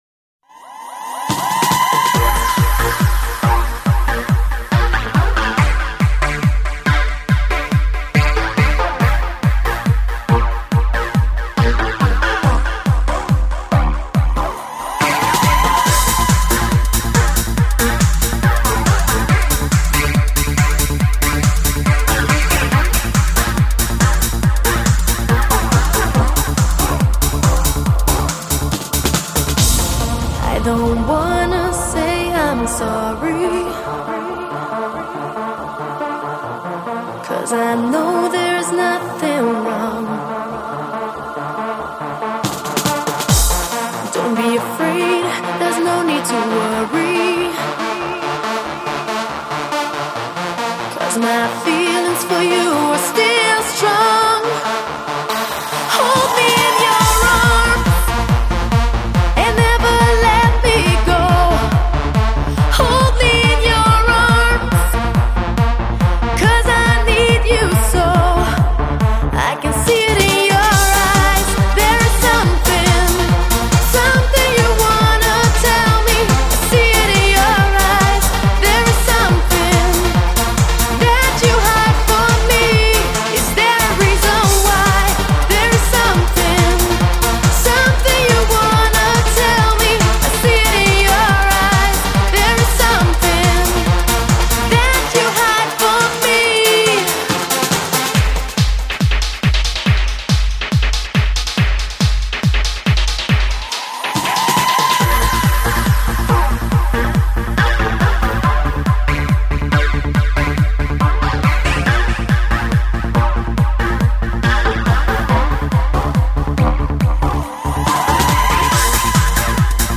TRANCE 狂飙电音横扫全球